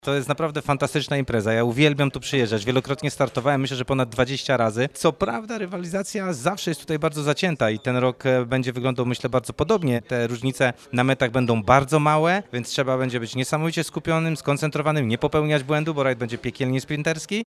Podczas konferencji prasowej w Urzędzie Miejskim w Świdnicy organizatorzy poinformowali o rekordowej liście zgłoszeń do kultowego rajdu odbywającego się w naszym regionie!